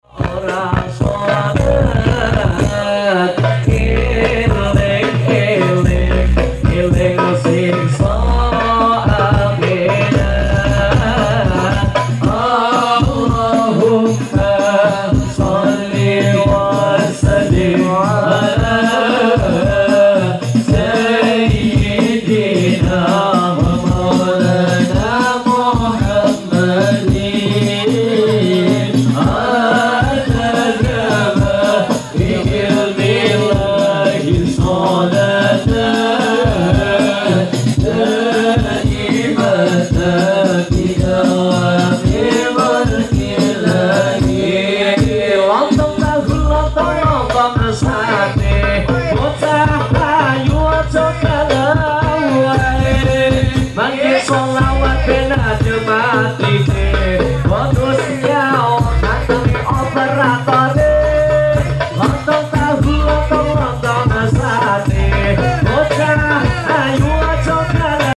Grub sholawatan Dari Bojonegoro Barat sound effects free download